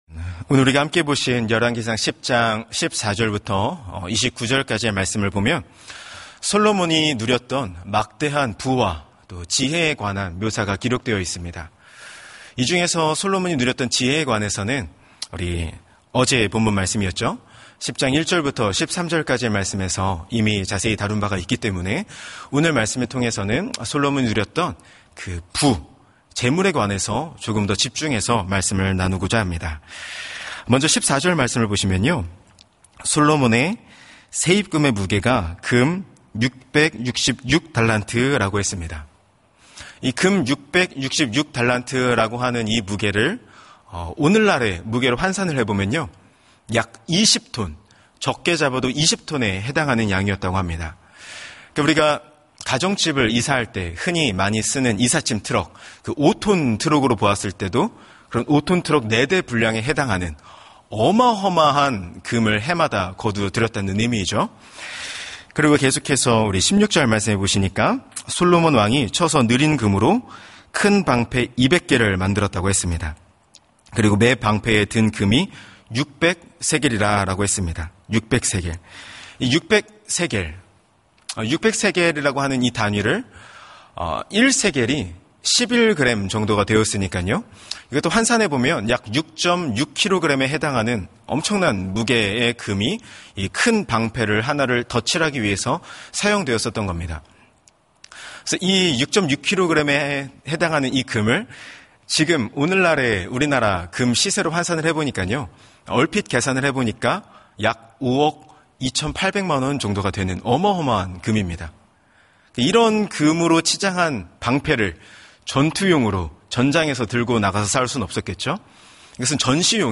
[삼일교회 삼일 교회] 금새벽 (금새벽)